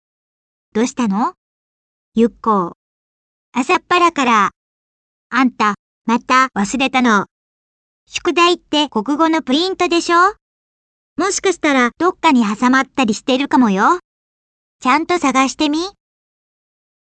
某動画投稿サイトで、「HOYAが開発したVoiceTextという合成音声のHARUKAが『日常』の長野原みおの声に似ている」と知り、丁度ちゃんみお（誤字ではない）の高音が不安定気味でだみ声混じりの特徴的な声が良いなあと思っていたところだったので私も試してみました。
こんなに自然に喋るとは！
パソコンとソフトだけで任意の文章を即座に流暢な言葉で話すのはすごい。
ちゃんみおよりも声は低いけど、声質にそれっぽさがあります。